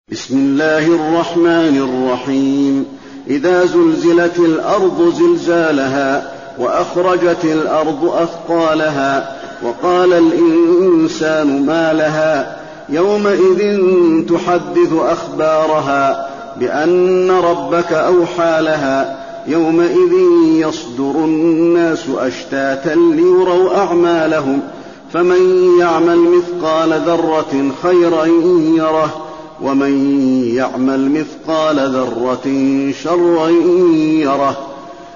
المكان: المسجد النبوي الزلزلة